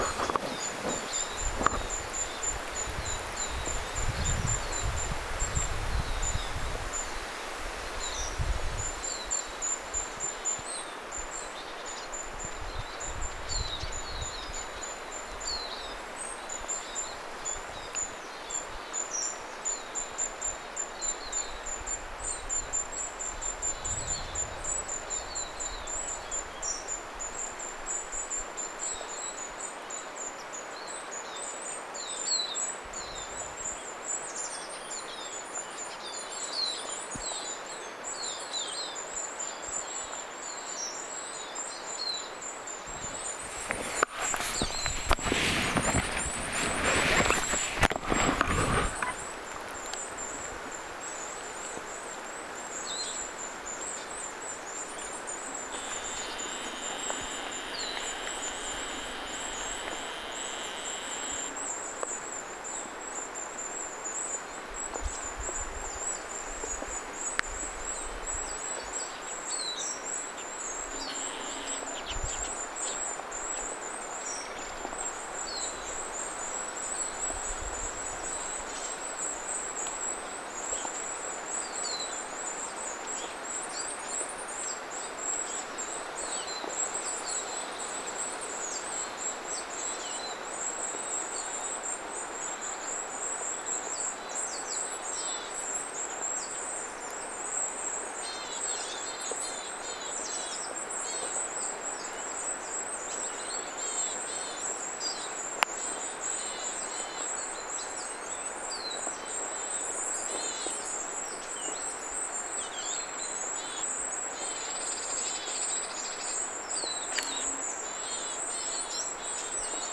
Hippiäiset ja pikkulinnut huomioääntelivät
kiivaasti sen nähdessään.
hippiäiset, pikkulinnut huomioääntelevät
suopöllön huomattuaan
hippiaiset_pikkulinnut_huomioaantelevat_kun_nakevat_kuvien_suopollon.mp3